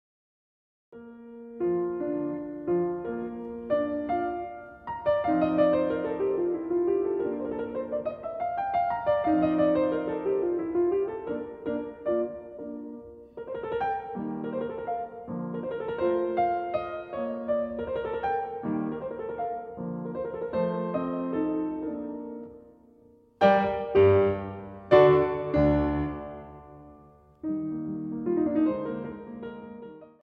short pieces for the piano
Allegro 8:45